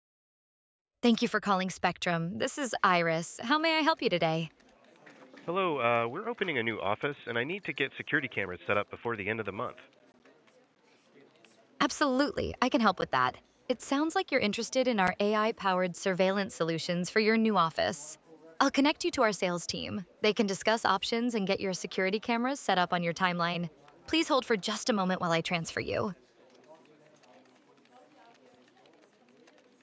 Hear how AI answers real calls
• Natural, conversational voice